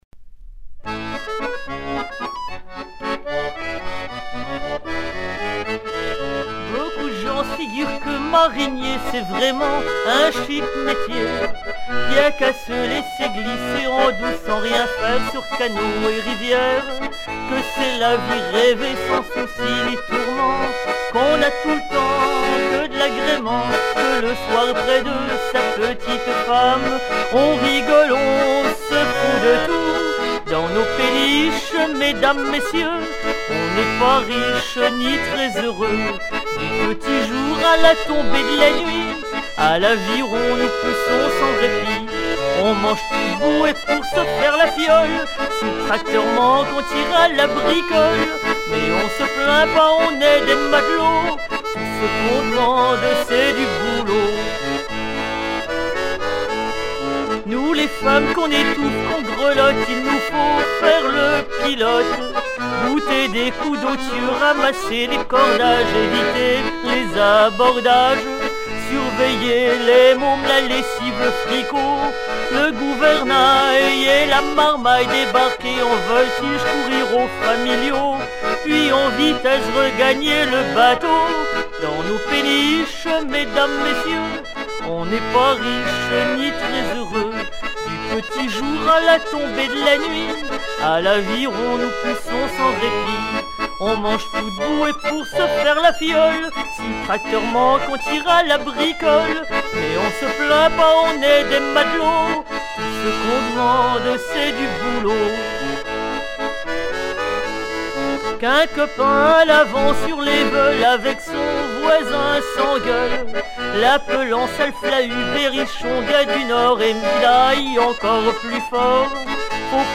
Version recueillie en 1985
marinier dunkerquois
Genre strophique
Chants de mariniers